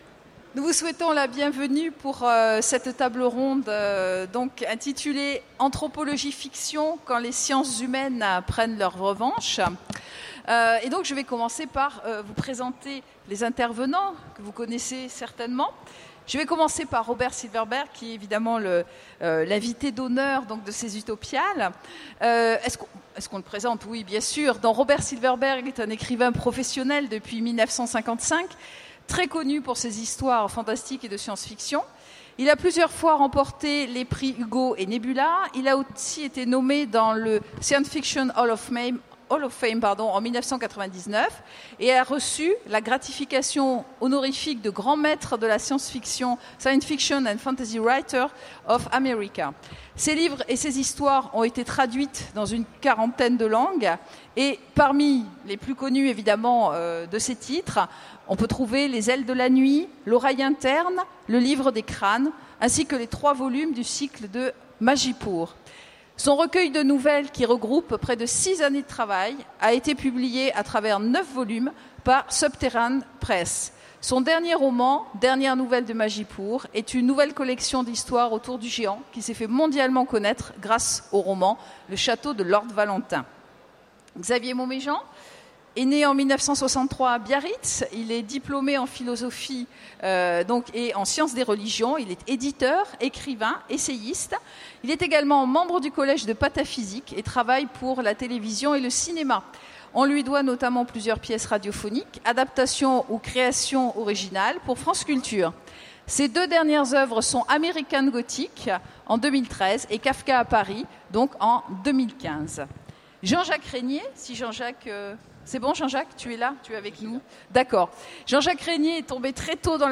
Utopiales 2015 : Conférence Anthropologie-fiction